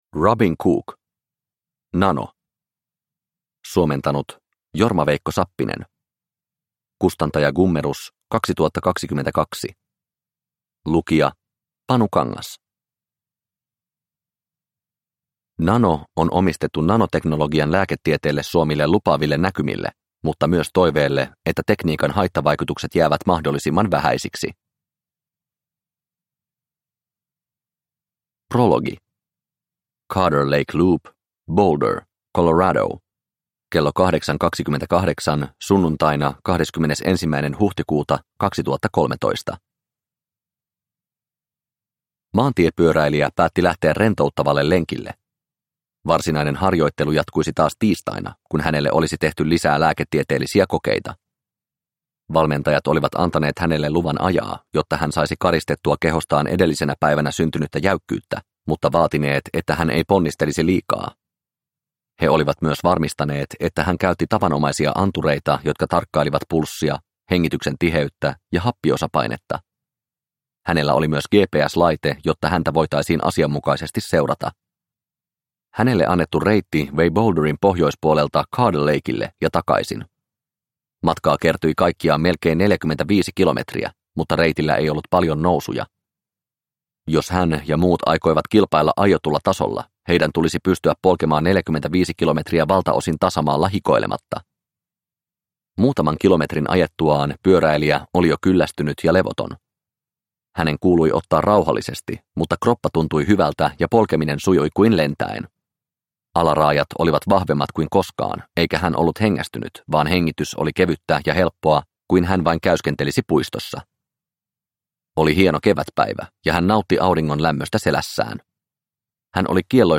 Nano – Ljudbok – Laddas ner